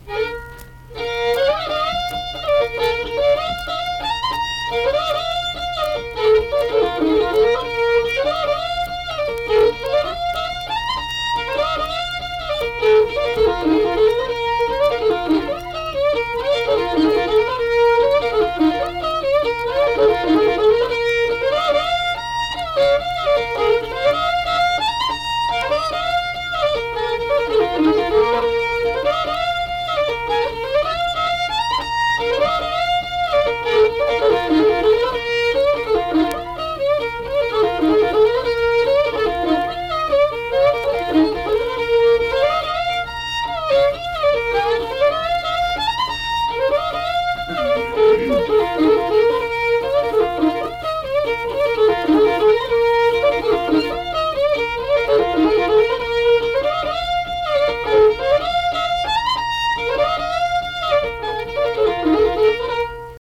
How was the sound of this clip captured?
Braxton County (W. Va.)